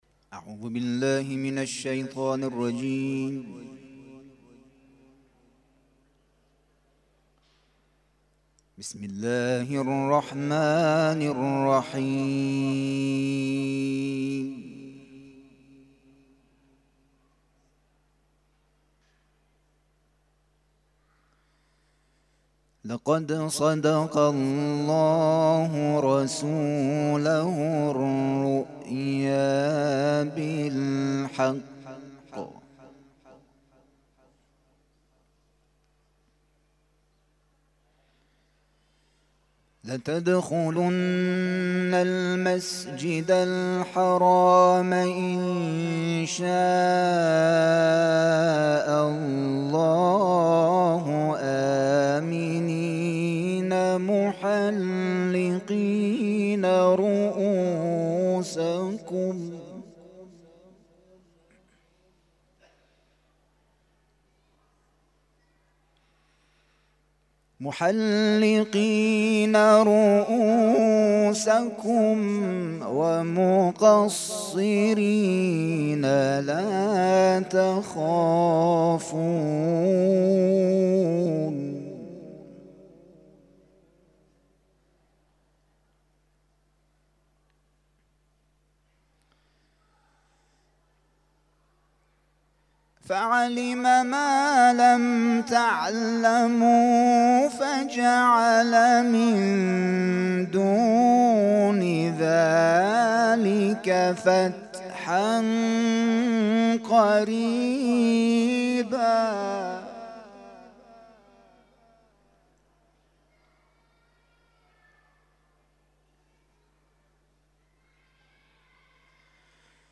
تلاوت ظهر - سوره فتح آیات (۲۷ الی ۲۹) Download